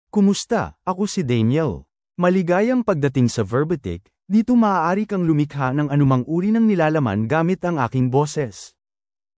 MaleFilipino (Philippines)
DanielMale Filipino AI voice
Daniel is a male AI voice for Filipino (Philippines).
Voice sample
Listen to Daniel's male Filipino voice.
Daniel delivers clear pronunciation with authentic Philippines Filipino intonation, making your content sound professionally produced.